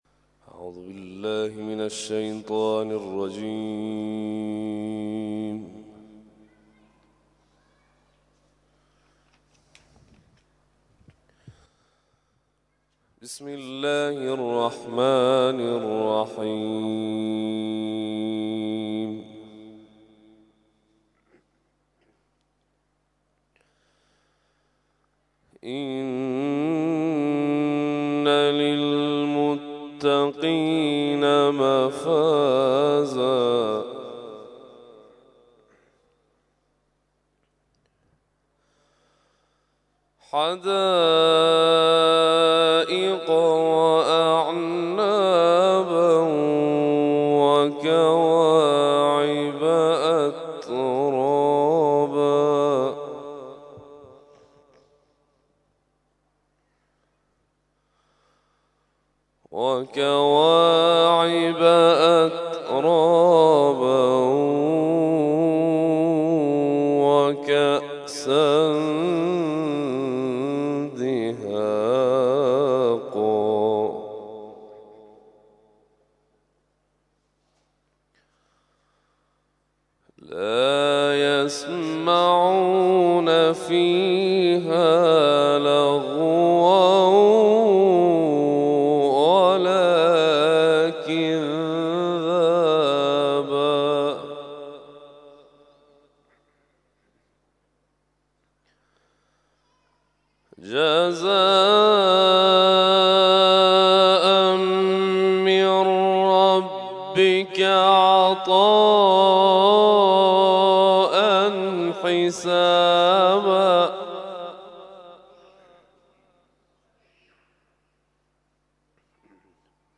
تلاوت صبح - سوره نبأ آیات ( ۳۱ الی ۴۰ ) و سوره حمد آیات ( ۱ الی ۷ )